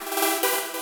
Помогите повторить вот такой звук. Интересует: сколько здесь осциляторов, голосов, детюна, одна нота или несколько, вейвформа (похоже на простую пилу, но может здесь что-то особенное?) были ли использованы фильтры и эффекты (кроме очевидного дилея)?